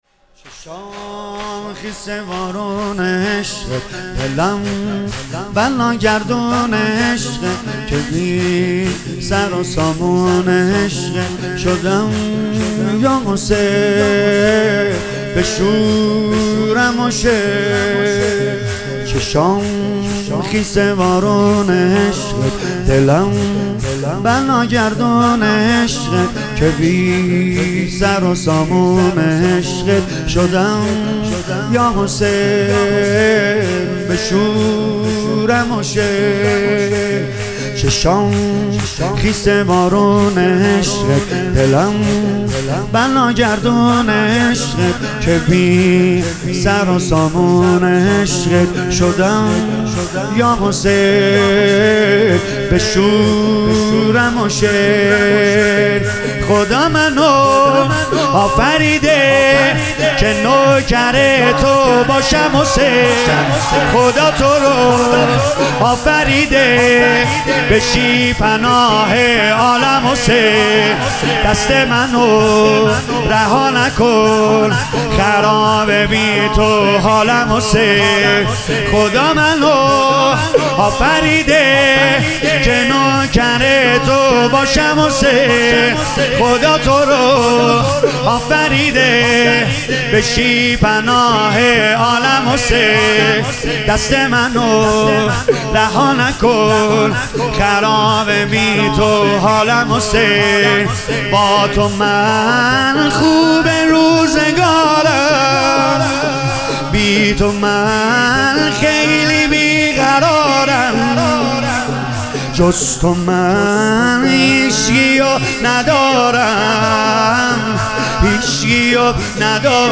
با صدای مداح اهل بیت
عزاداری محرم